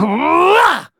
Kibera-Vox_Attack4.wav